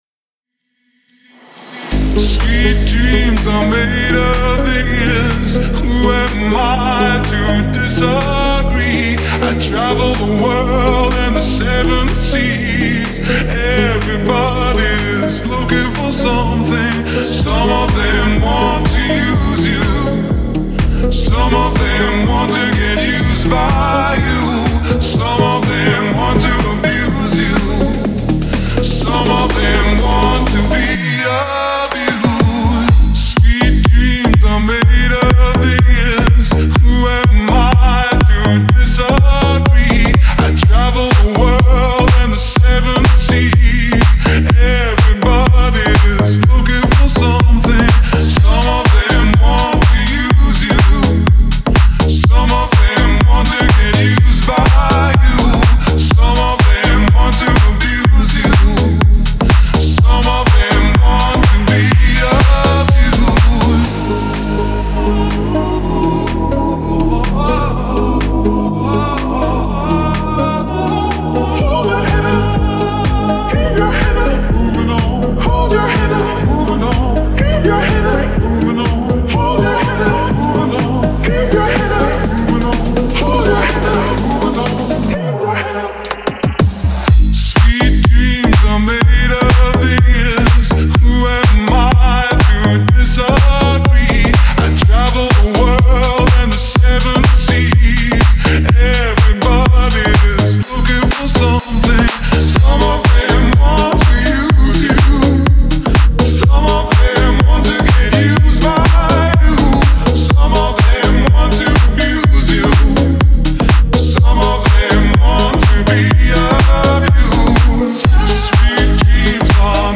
• Жанр: Pop, Dance